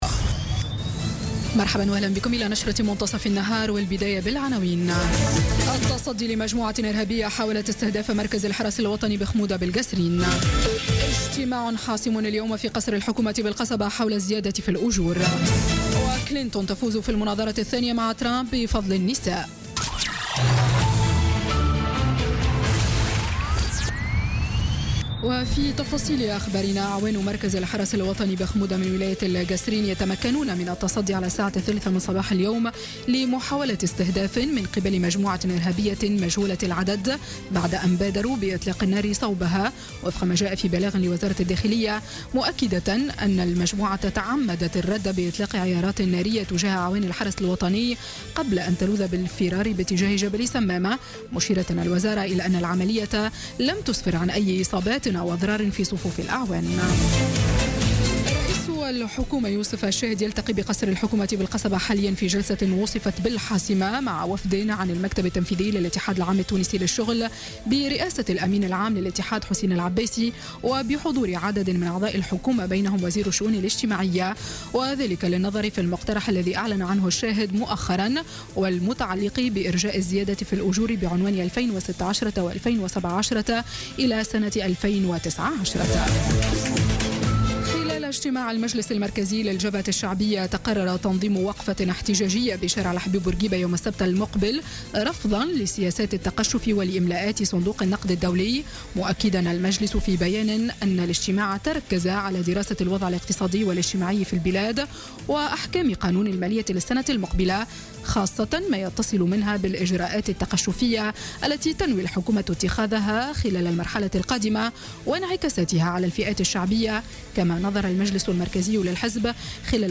Journal Info 12h00 du lundi 10 octobre 2016